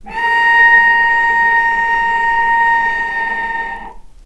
vc-A#5-mf.AIF